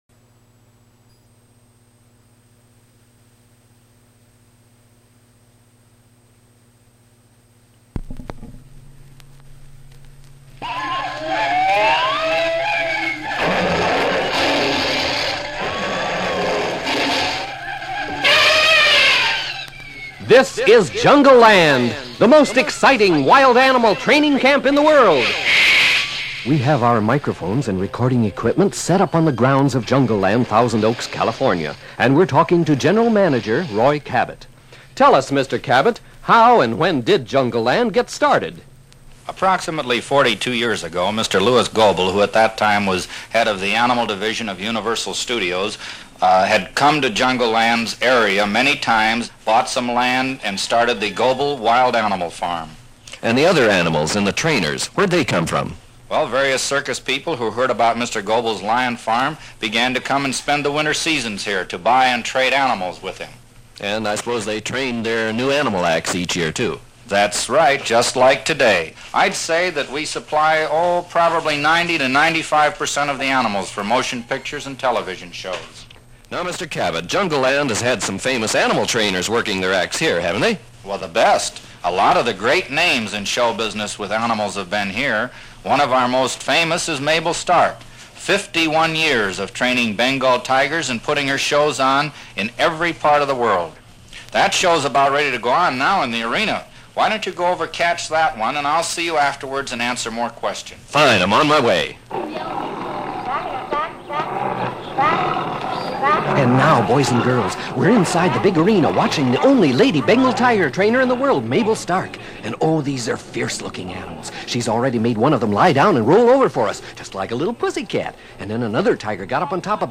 For those who couldn’t visit Disneyland by sight, he made it possiblecould by listening to the narrator and the different animals of Jungleland. This was a first, to help those with this type of handicap.